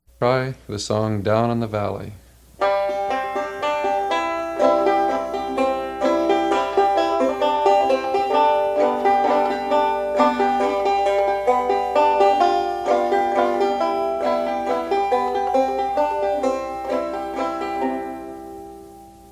Traditional
This song is in 3/4 waltz time.
Listen to Pete Seeger play "Down In The Valley" with double-thumbing (mp3)
seeger_down_valley.mp3